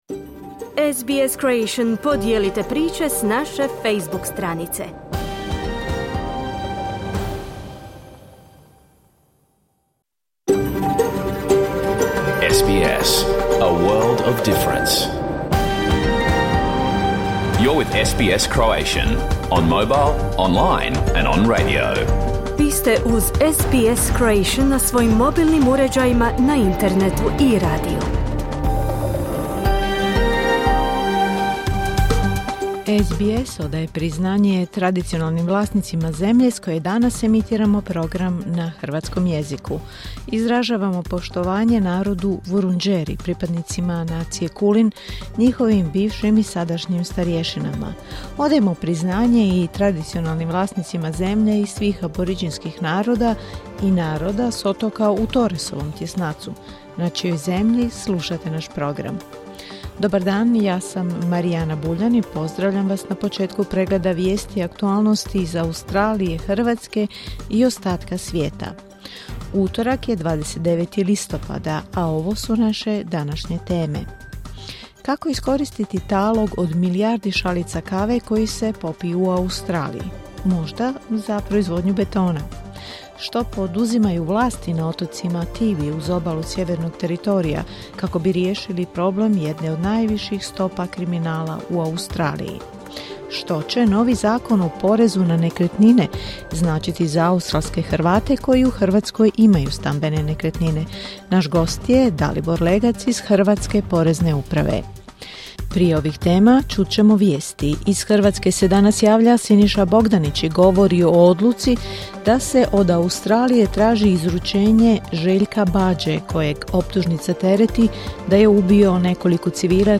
Vijesti i aktualnosti iz Australije, Hrvatske i ostatka svijeta. Emitirano uživo u utorak, 29. listopada, u 11 sati.